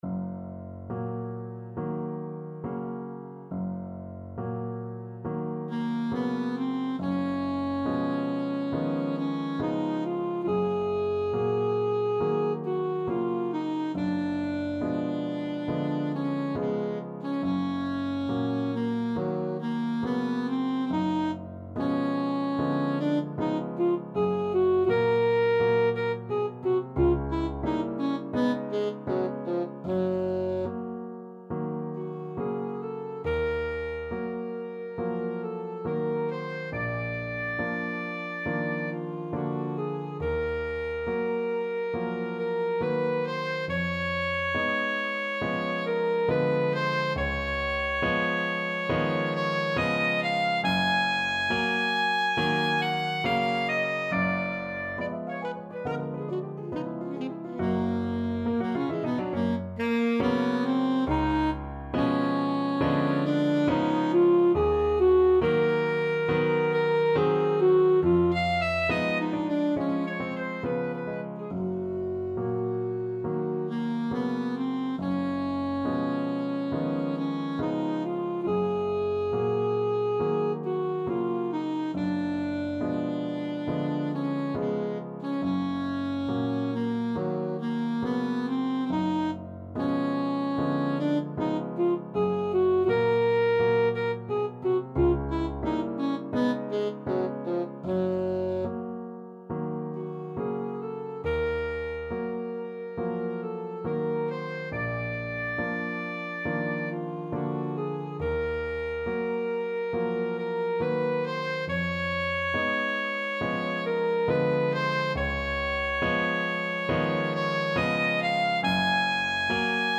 Classical Clara Schumann Piano Concerto in Am (Op.7) Second Movement Main Theme Alto Saxophone version
Alto Saxophone
Andante non troppo con grazia =69
Gb major (Sounding Pitch) Eb major (Alto Saxophone in Eb) (View more Gb major Music for Saxophone )
Classical (View more Classical Saxophone Music)